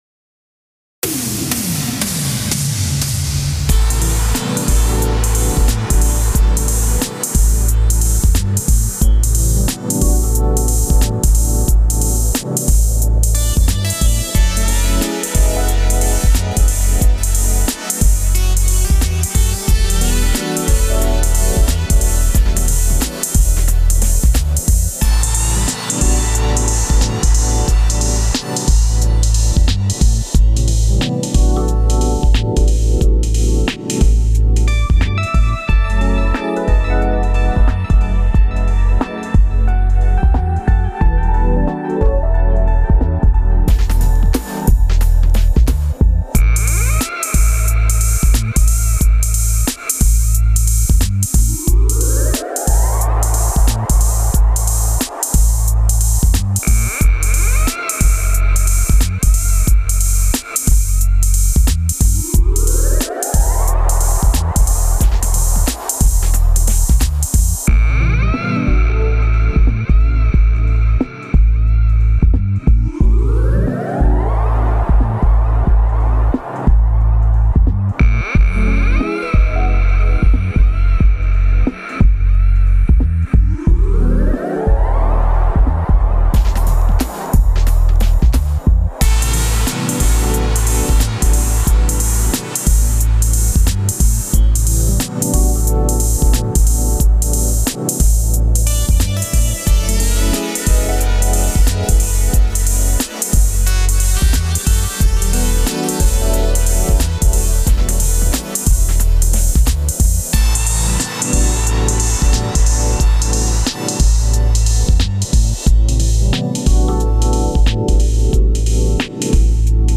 E.A.S. | Electronic Ambient Space - Electronic - Young Composers Music Forum